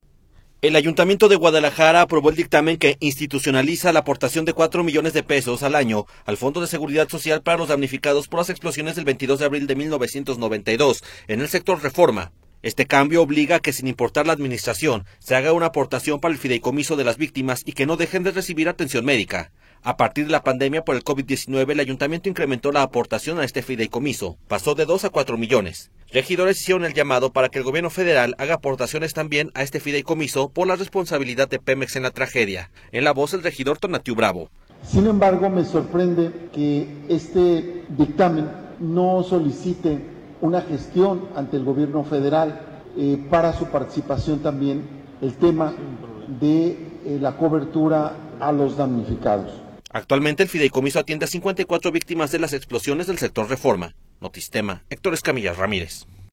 Regidores hicieron el llamado para que el Gobierno federal haga aportaciones también a este fideicomiso, por la responsabilidad de Pemex en la tragedia. En la voz el regidor Tonatiuh Bravo.